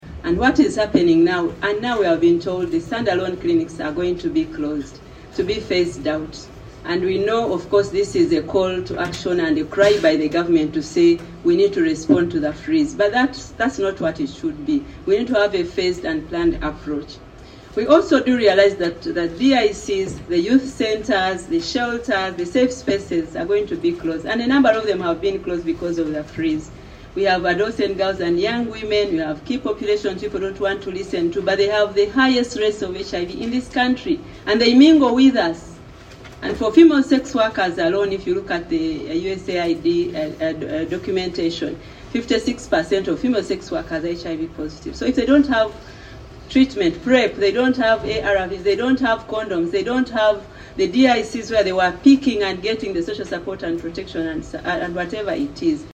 Officials from the two organisations made this appeal on Tuesday, 11 February 2025 while appearing before the Committee on Health chaired by Hon. Joseph Ruyonga.